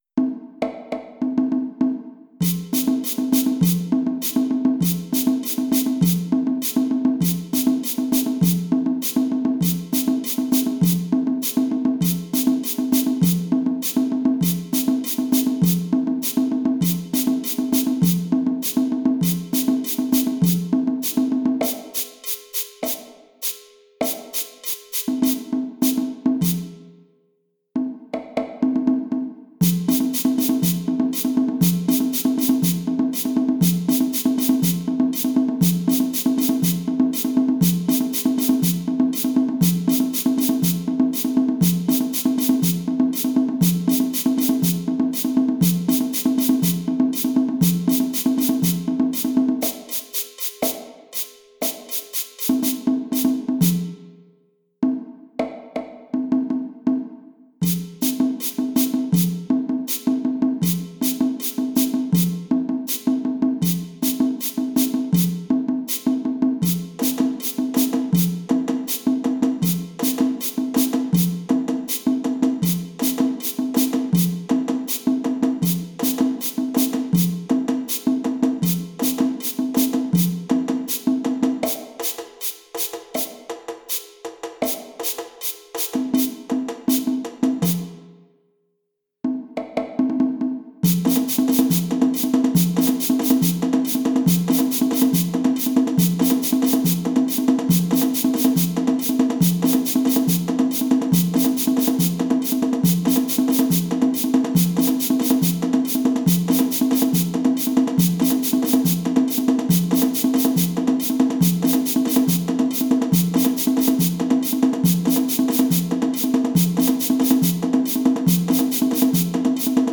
Lead drum, bell, + rattle (audio)                         Lead drum + stick drum (audio)                                  Lead drum, bell, rattle, + stick drum (audio)